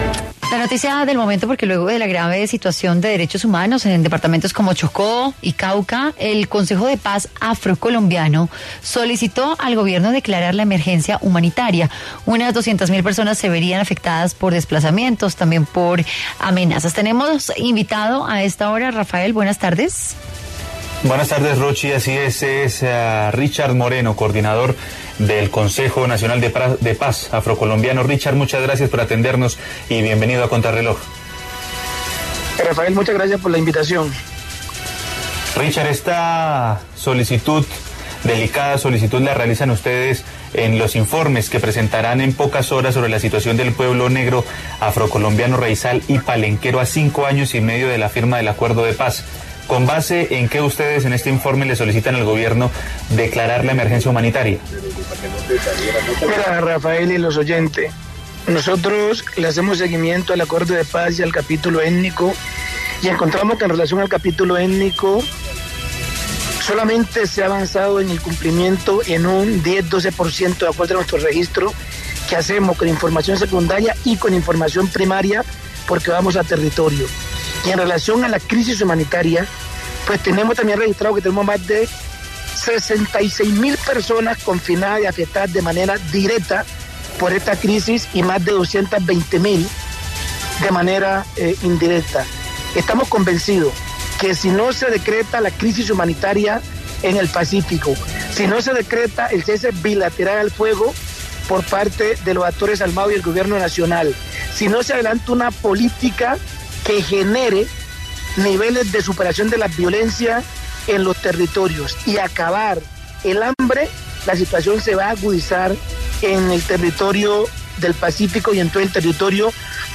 En diálogo con Contrarreloj